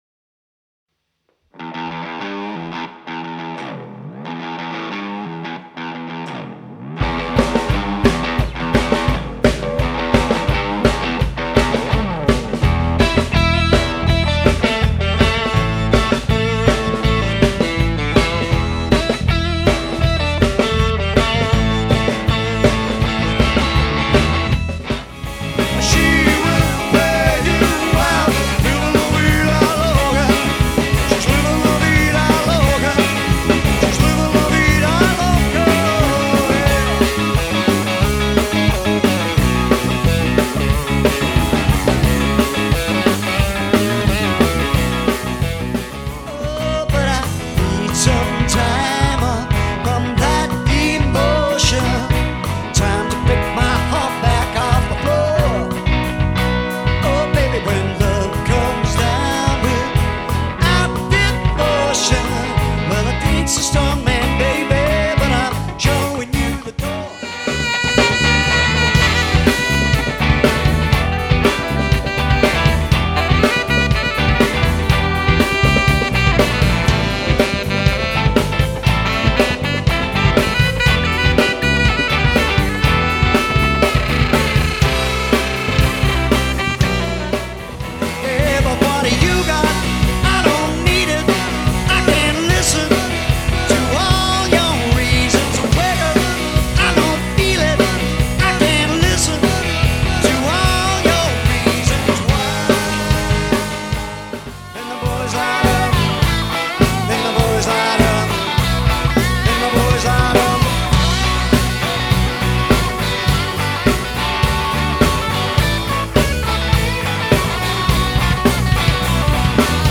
vocals sax, flute, guitar
piano, keys, vocals
drums, vocals